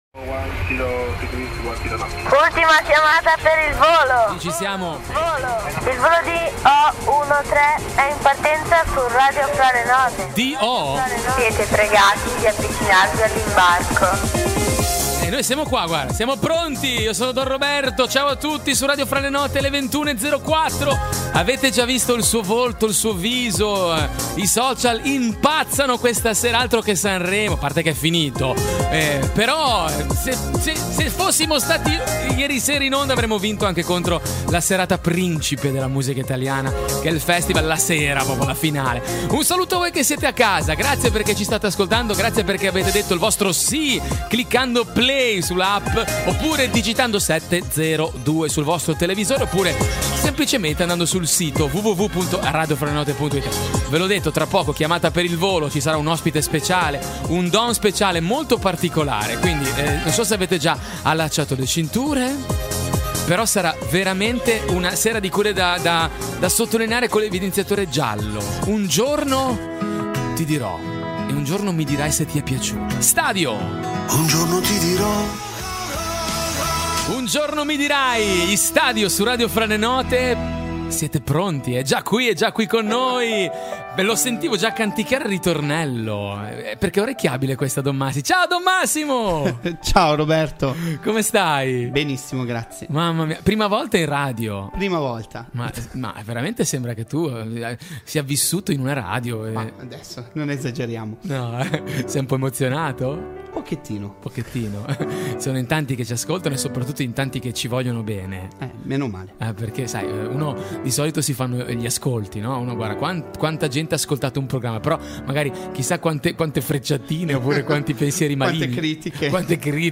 In onda la domenica sera alle 21:00 in diretta dalla Sede centrale di Radio Fra le note in Via Minoretti di Genova.